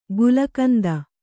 ♪ gulakanda